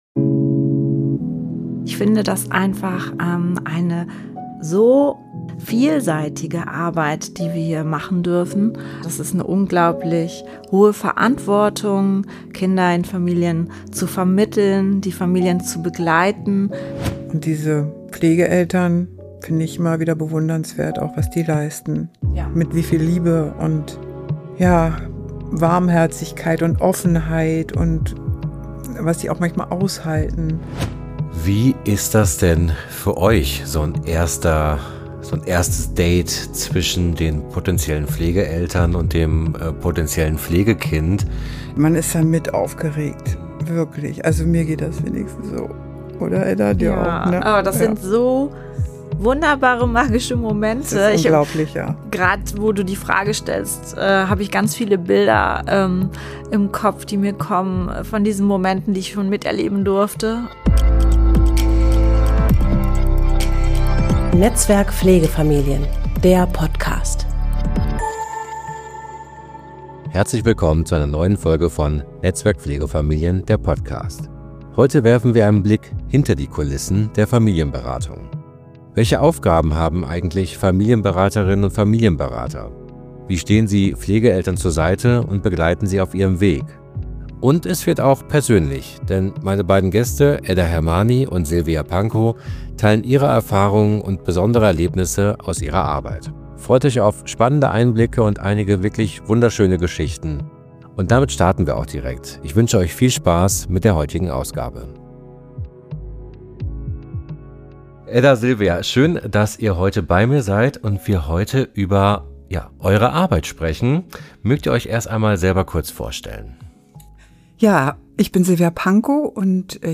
Was macht eigentlich eine Familienberaterin oder ein Familienberater? In dieser Folge sprechen wir mit zwei erfahrenen Familienberaterinnen über ihre Aufgaben, die Begleitung von Pflegefamilien und wertvolle Erfahrungen aus der Praxis.